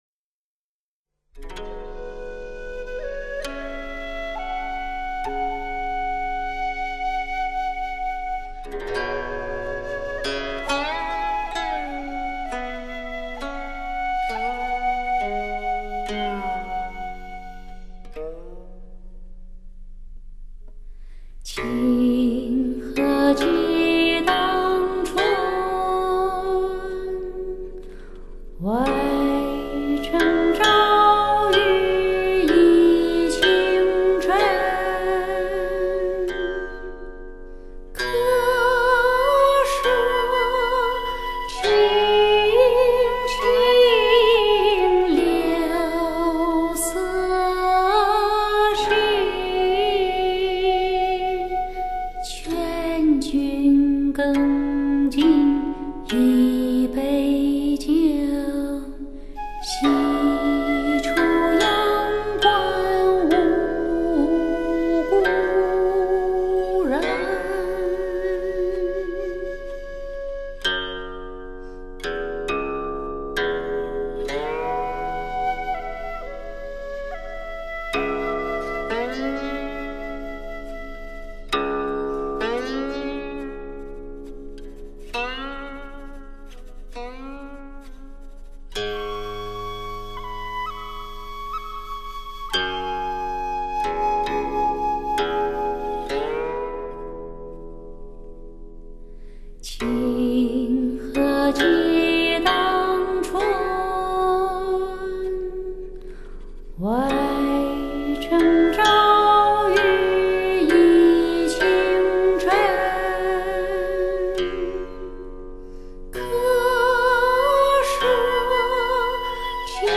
因此我们想将这两种乐器和这两种唱法结合到一起，以向我们的音乐祖先致敬。